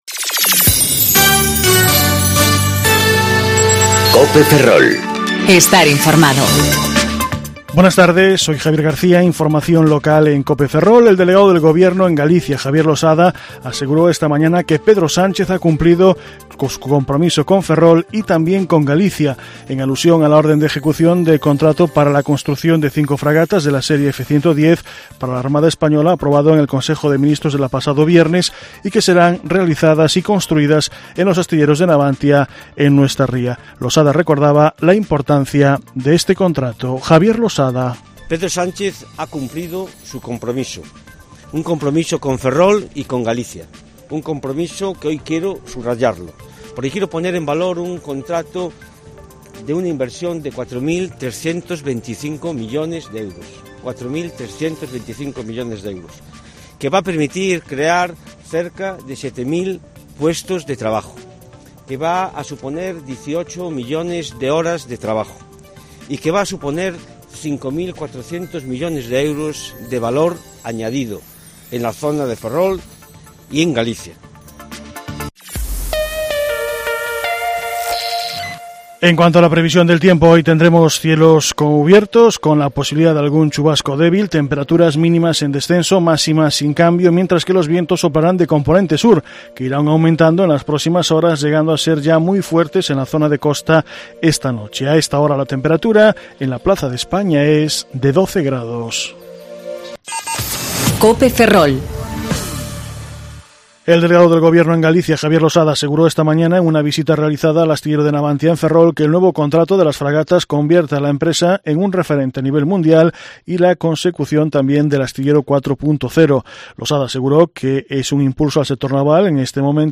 Informativo Mediodía Cope Ferrol